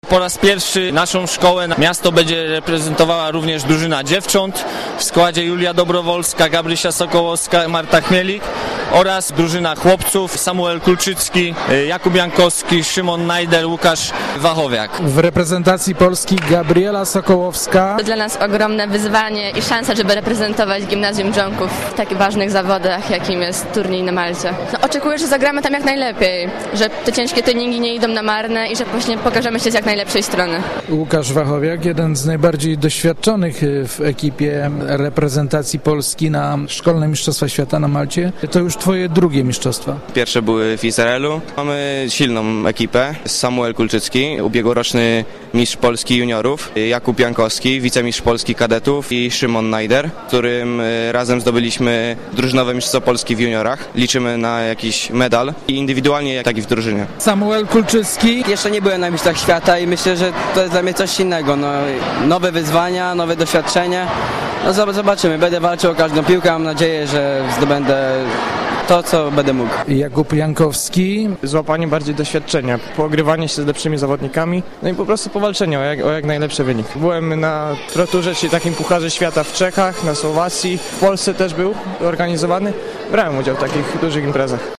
W hali sportowej Zespołu Edukacyjnego nr 4 w Drzonkowie odbyła się konferencja prasowa dotycząca wyjazdu reprezentacji na szkolne mistrzostwa świata w tenisie stołowym, które odbędą się na Malcie.